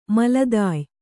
♪ maladāy